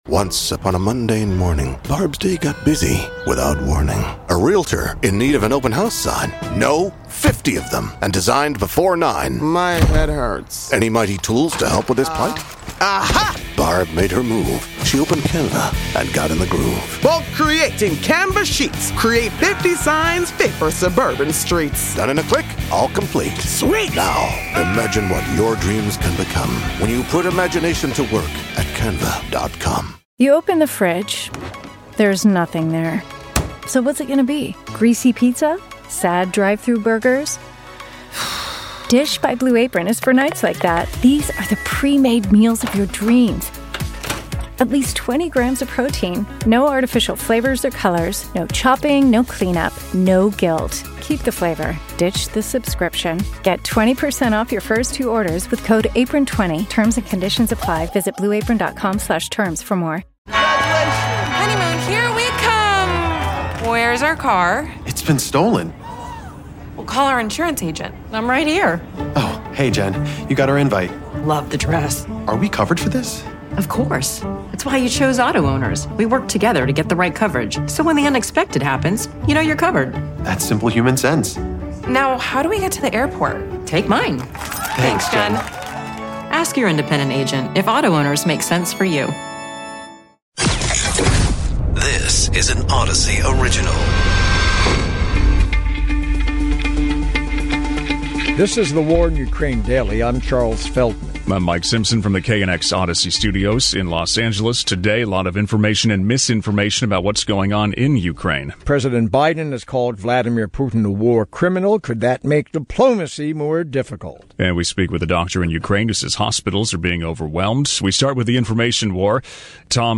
And we speak with a doctor in Ukraine who says hospitals are being overwhelmed.